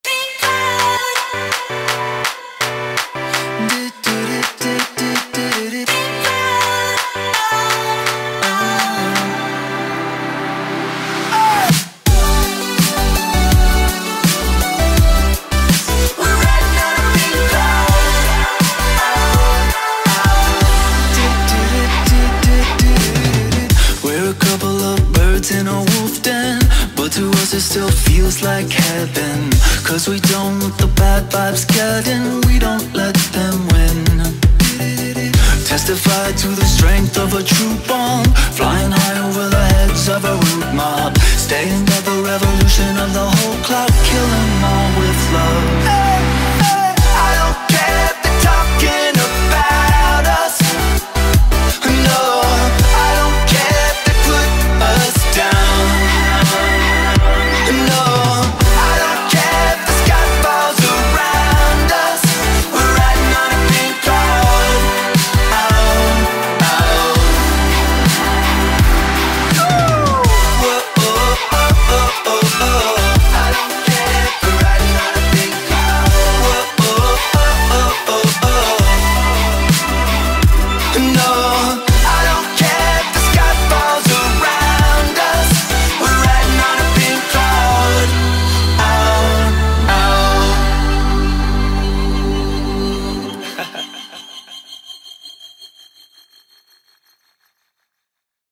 BPM83
Audio QualityMusic Cut